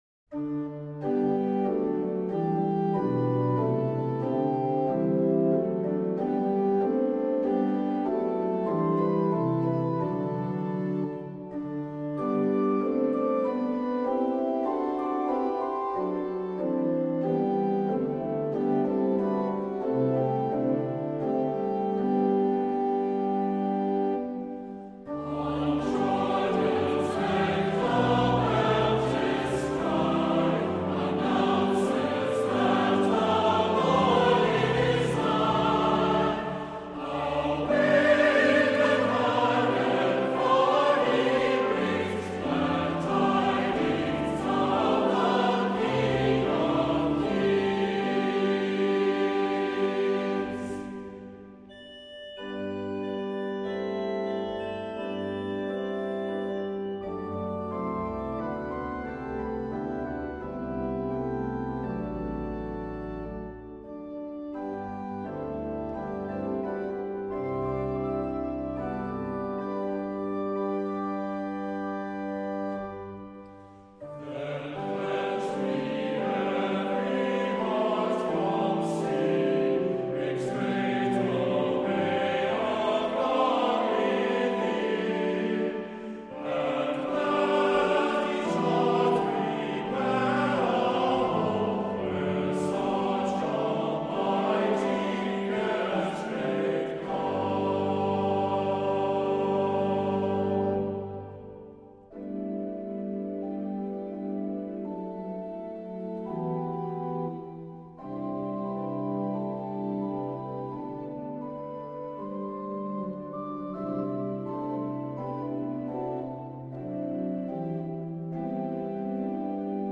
Voicing: Soprano Descant,SATB